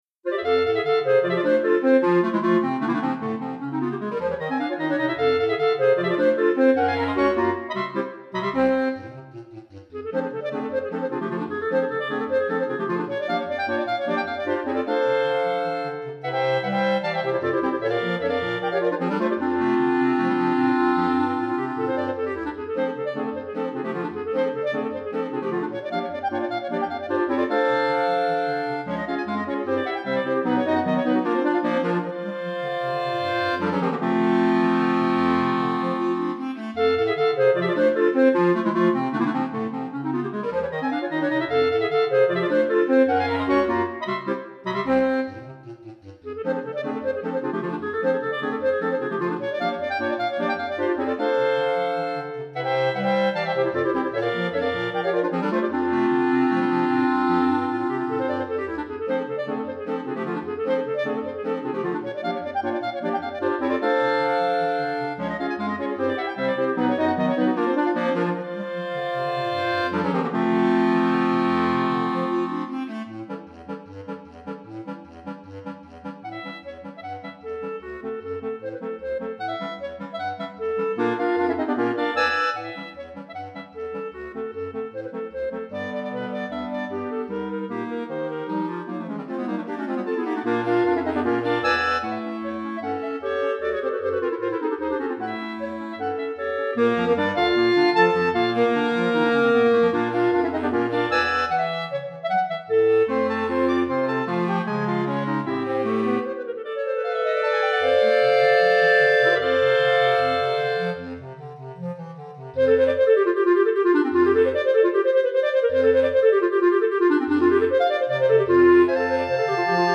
5 Clarinettes